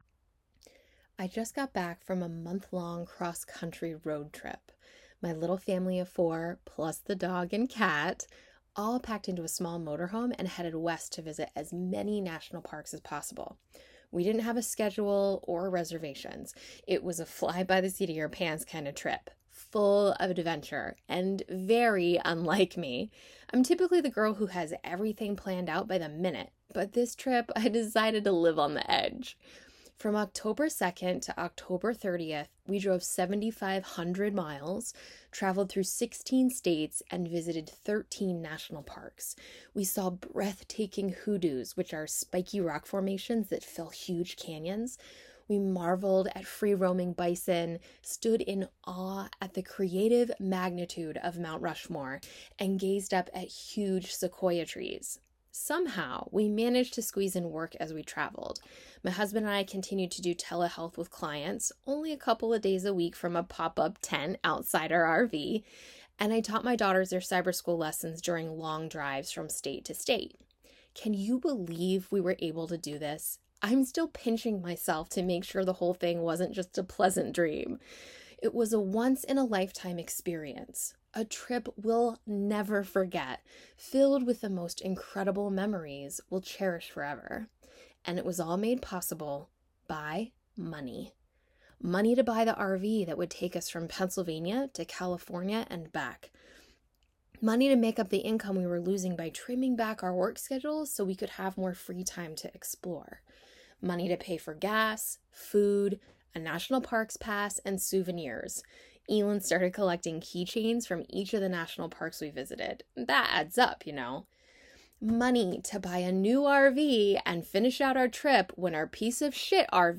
Press play and I’ll read this juicy article to you!